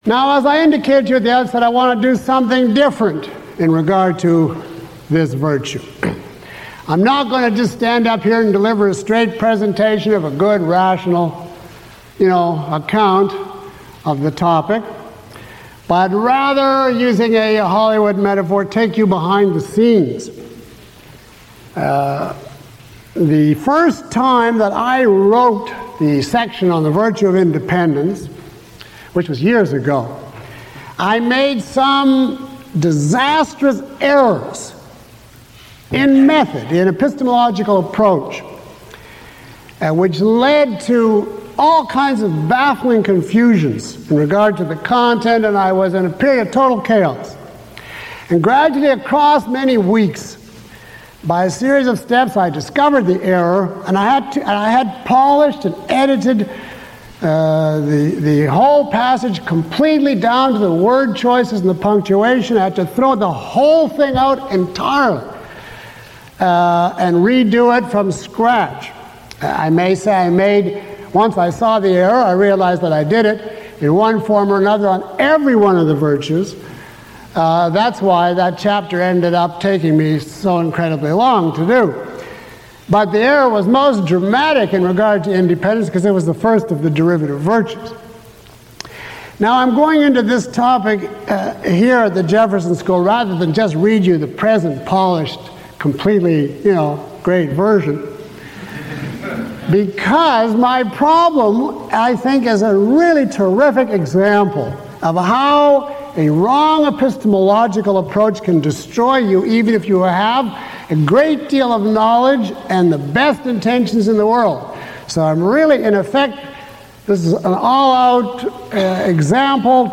Lecture 03 - Moral Virtue.mp3